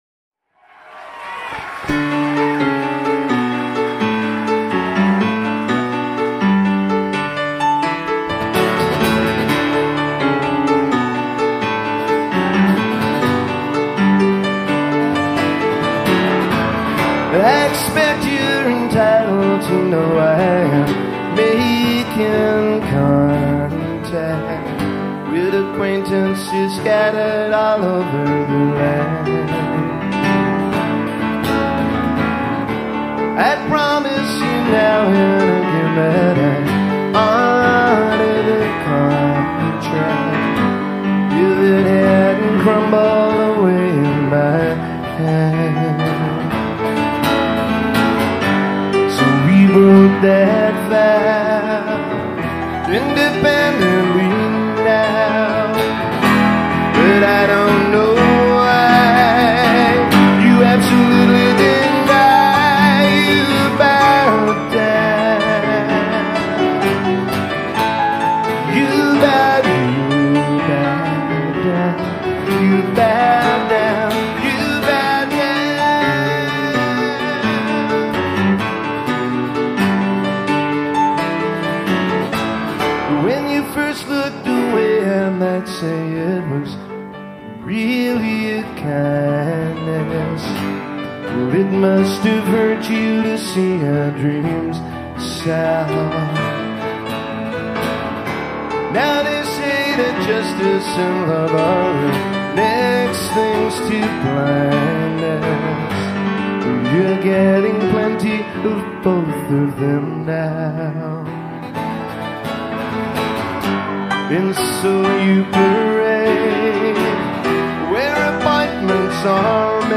pianist
an intimate acoustic show in five different cities